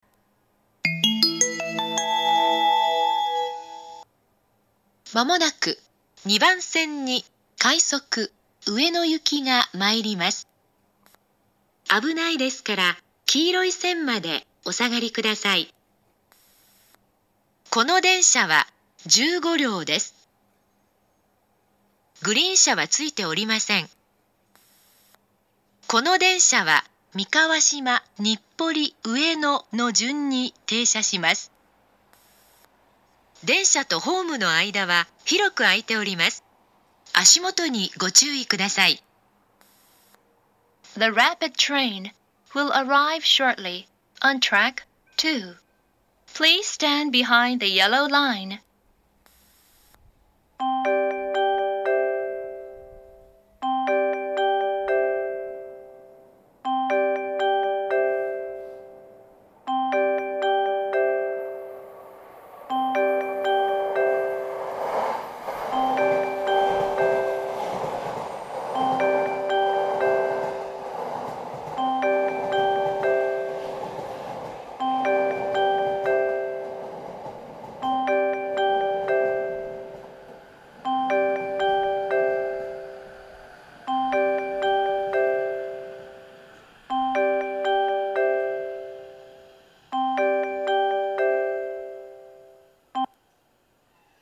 ２０１４年１２月１５日には、２０１５年３月開業の上野東京ラインに対応するため、自動放送の男声が変更されています。
２番線接近放送